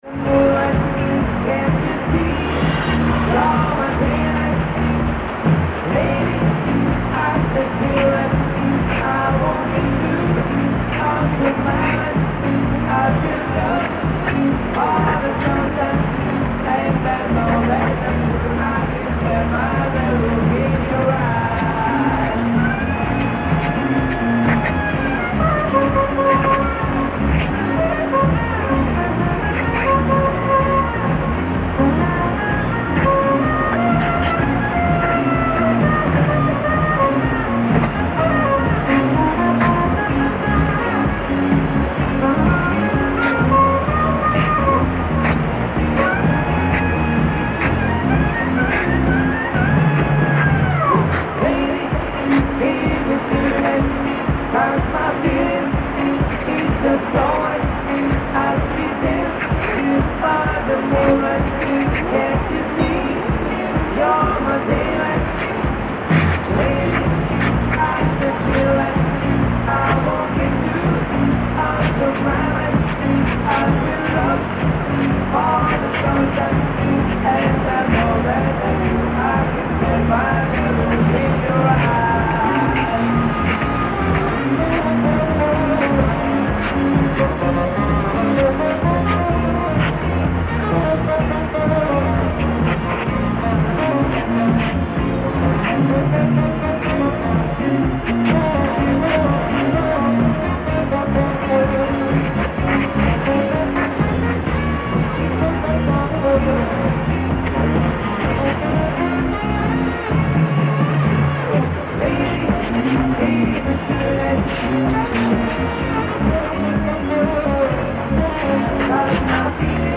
03:38 -0330- めちゃくちゃ強いのですが変調汚い。
12:20 テーマ音楽（ブリッジ音楽）同じですね。